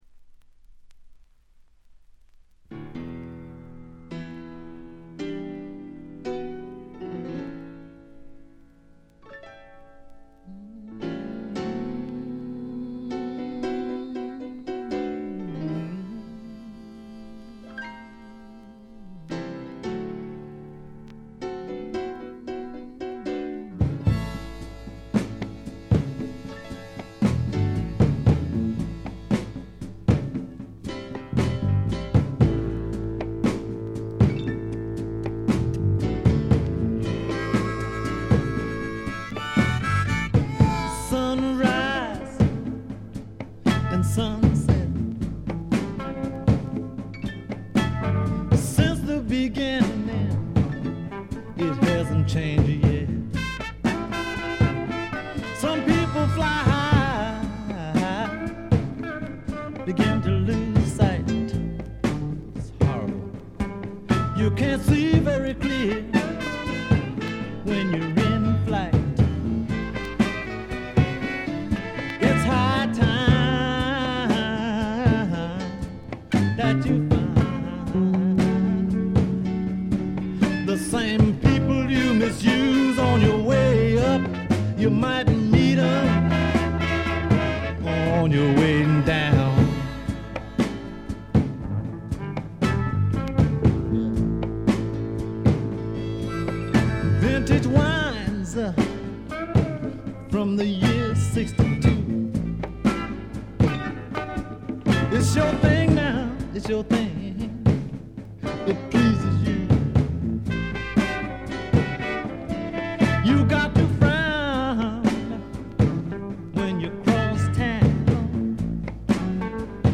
鑑賞を妨げるようなノイズはありません。
セカンドライン・ビート、ニューソウル的なメロウネス、何よりも腰に来るアルバムです。
試聴曲は現品からの取り込み音源です。